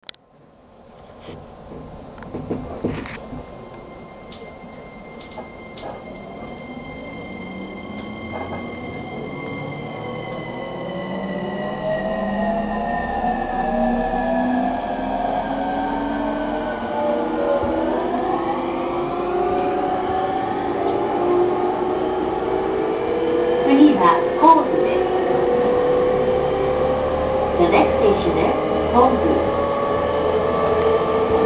（JR東海道線車内放送 31s.）5時45分頃に国府津駅に到着。